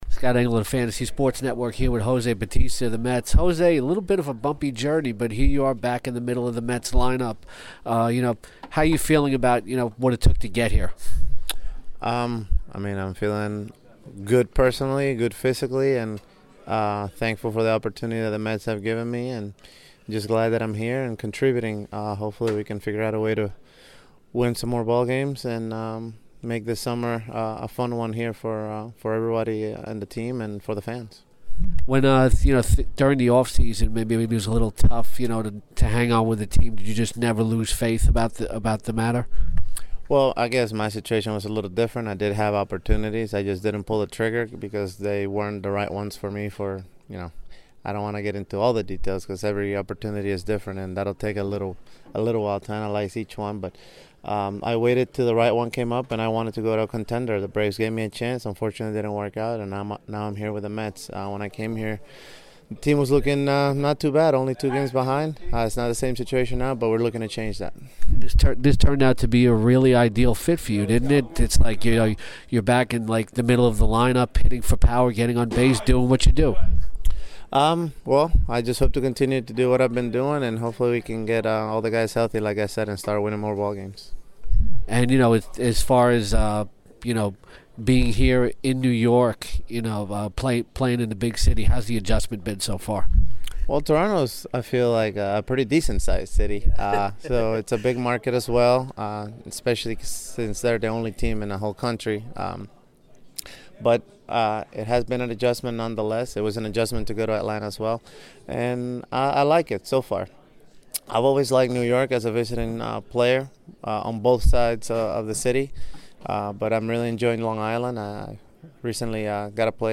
talks with Mets outfielder Jose Bautista